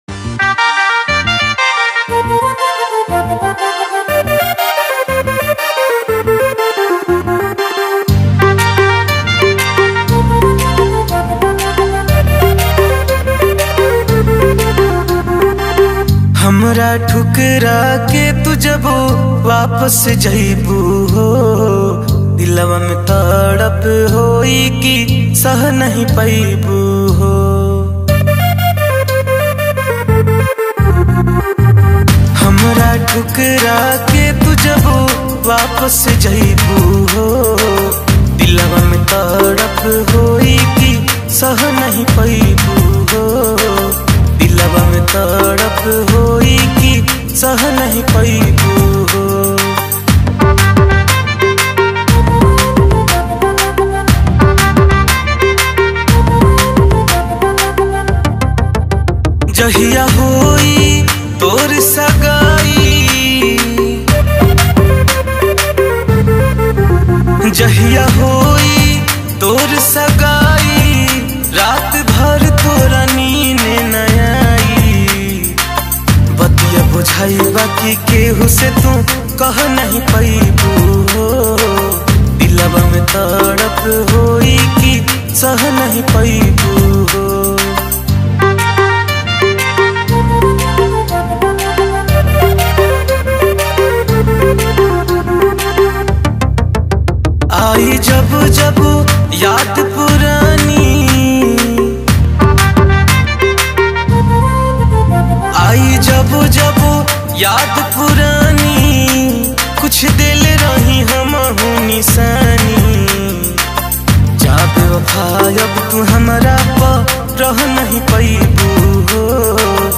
Releted Files Of Bhojpuri Mp3 Song